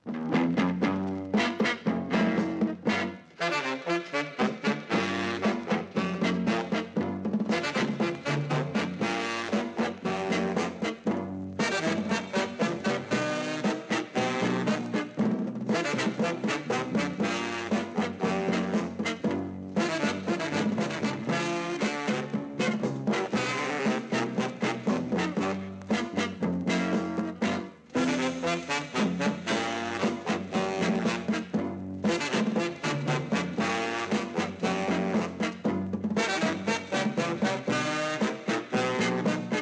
Mod & R&B & Jazz & Garage